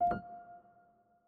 Sound / Effects / UI / Modern8.wav